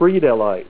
Help on Name Pronunciation: Name Pronunciation: Friedelite + Pronunciation
Say FRIEDELITE